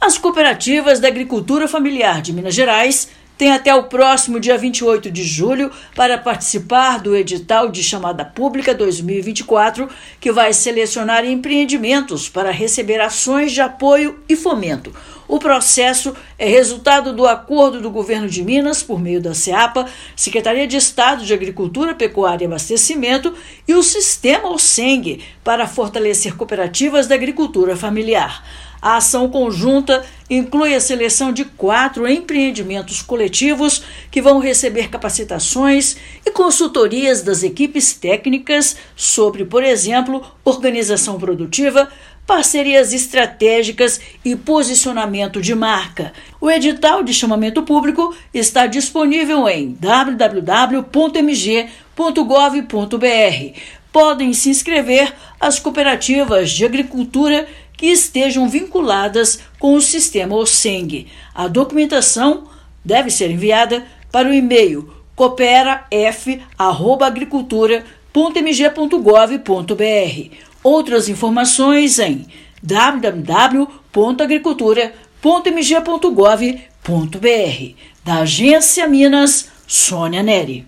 Parceria entre o Governo de Minas e o Sistema Ocemg vai selecionar quatro cooperativas mineiras da agricultura familiar para receberem capacitação técnica. Ouça matéria de rádio.